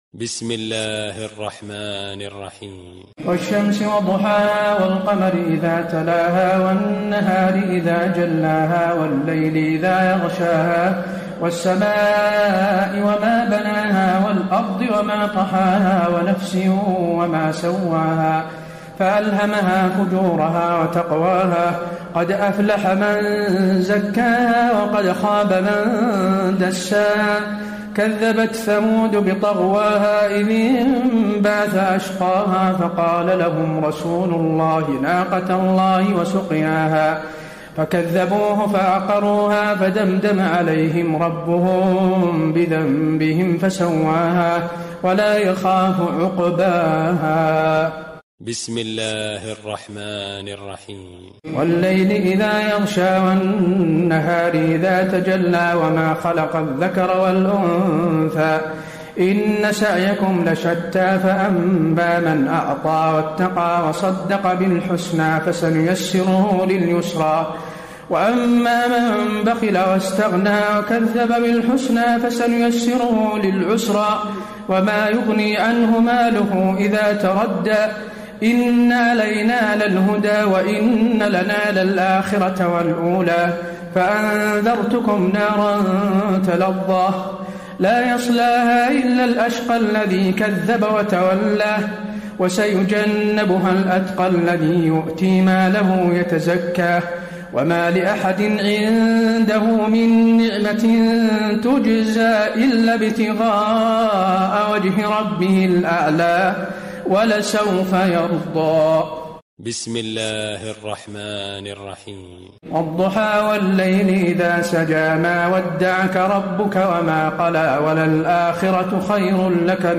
تراويح ليلة 29 رمضان 1434هـ من سورة الشمس الى الناس Taraweeh 29 st night Ramadan 1434H from Surah Ash-Shams to An-Naas > تراويح الحرم النبوي عام 1434 🕌 > التراويح - تلاوات الحرمين